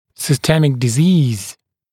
[sɪ’stemɪk dɪ’ziːz][си’стэмик ди’зи:з]системное заболевание